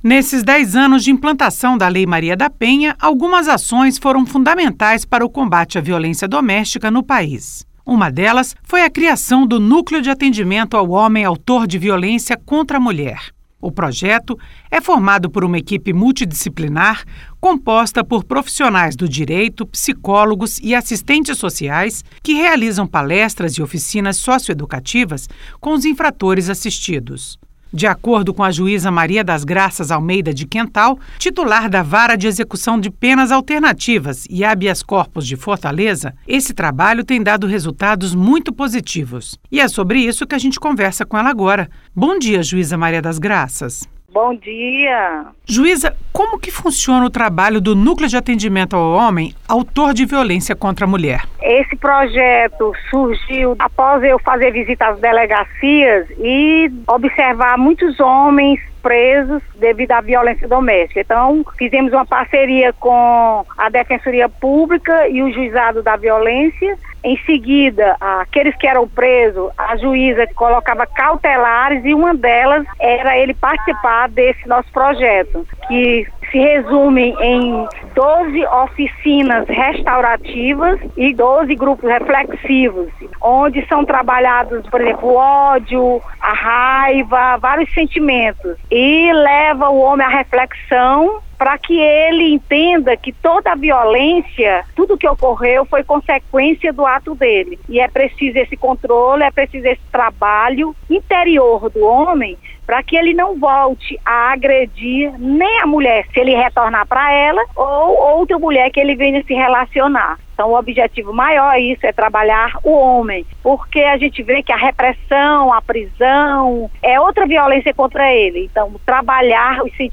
* Entrevista originalmente veiculada no dia 15 de agosto de 2016